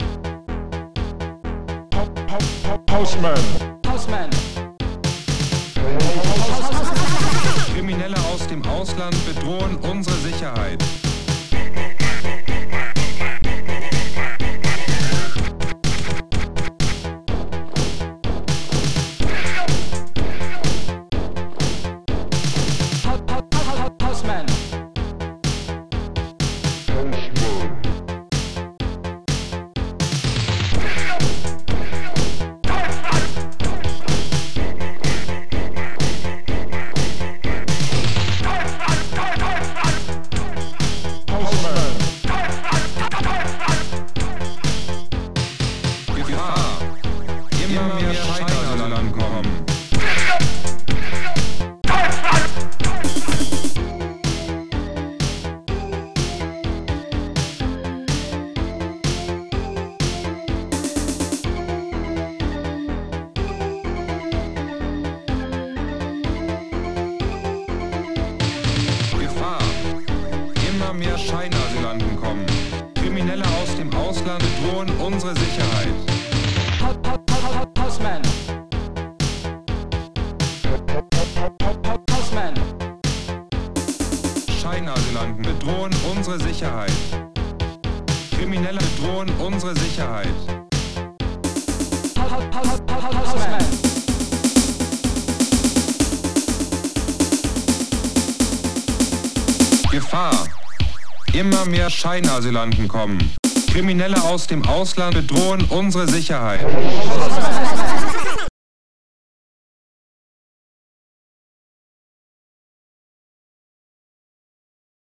Protracker Module
touch bass